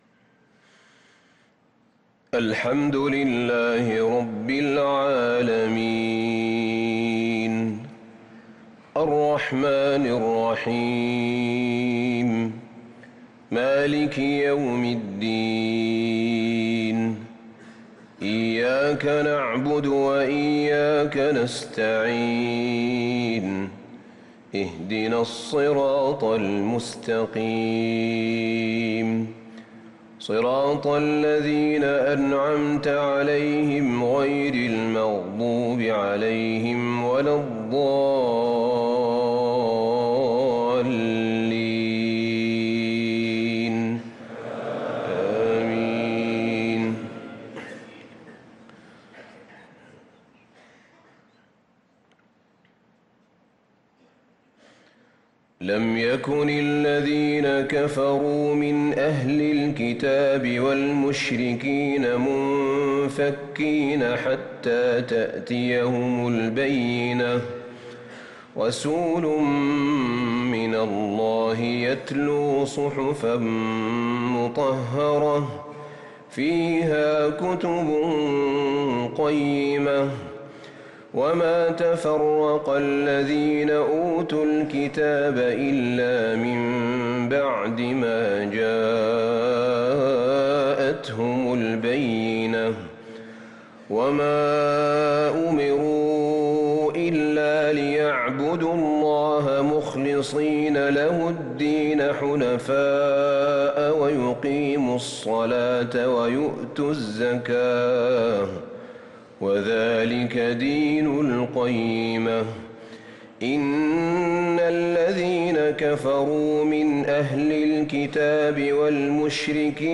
صلاة المغرب للقارئ أحمد الحذيفي 20 ذو الحجة 1444 هـ